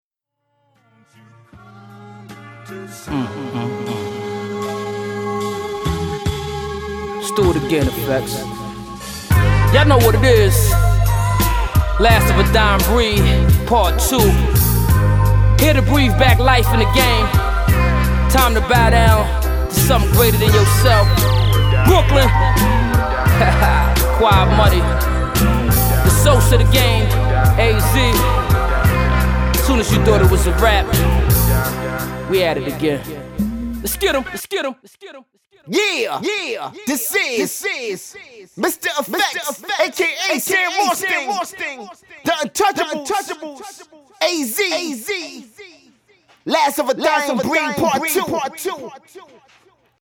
It’s a very smooth listen.